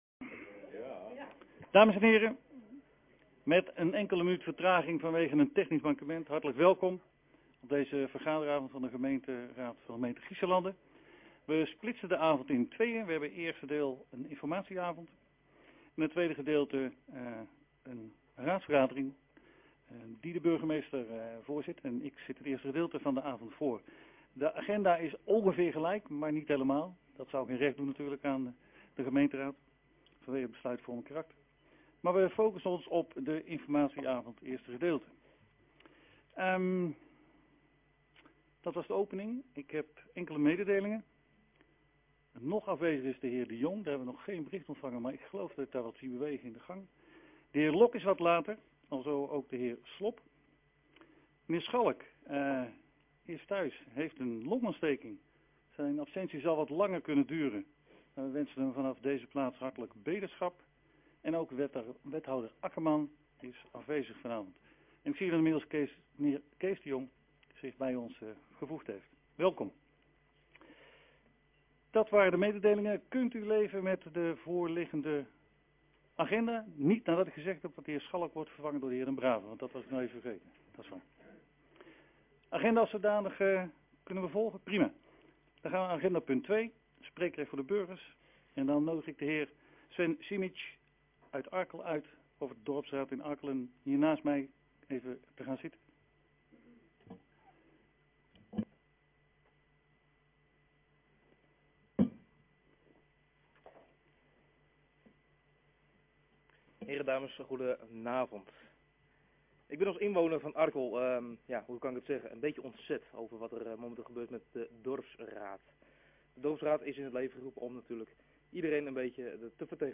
Hoornaar, gemeentehuis - raadzaal